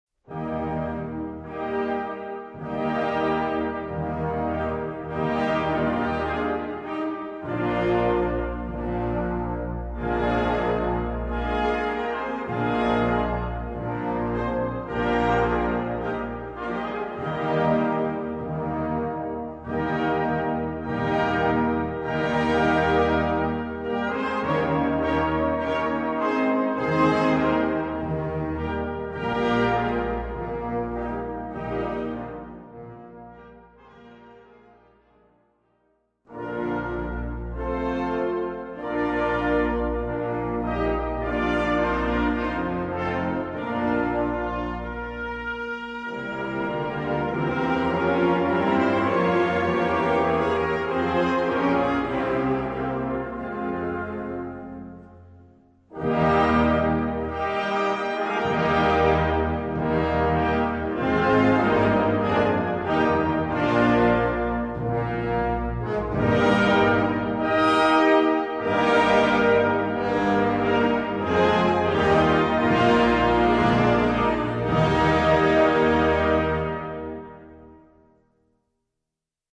Gattung: Trauermarsch
Besetzung: Blasorchester